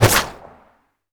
bullet_leave_barrel_05.wav